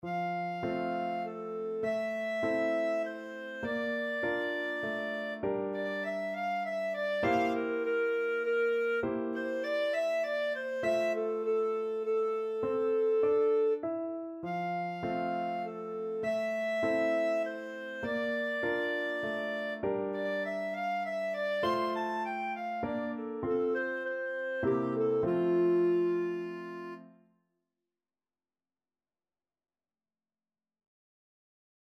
Clarinet version
3/4 (View more 3/4 Music)
Moderato
Classical (View more Classical Clarinet Music)